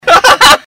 Laugh 23